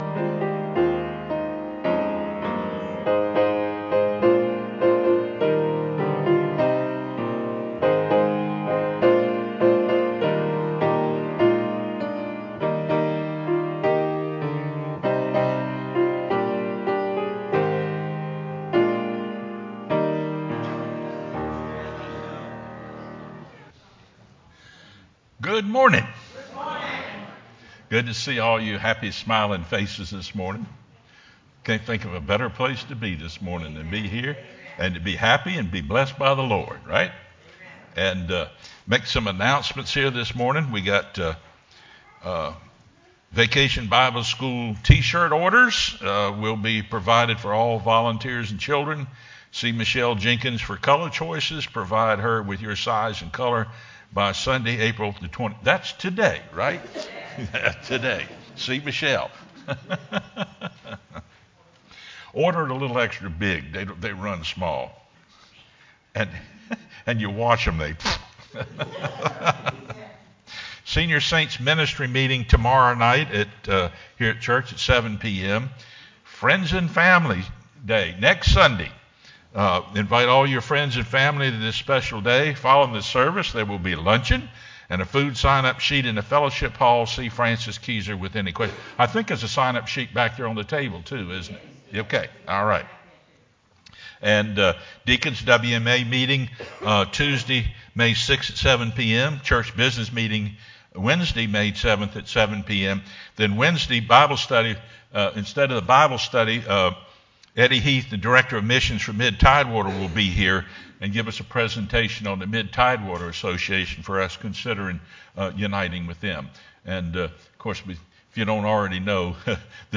sermonApr27-CD.mp3